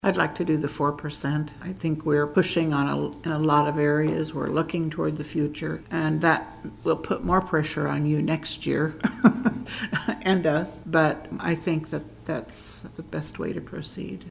Additionally on Tuesday, commissioners discussed the 2023 water, wastewater and stormwater utilities and updates to rates and charges for 2024.
Commissioner Linda Morse said she favors moving ahead with just 4%.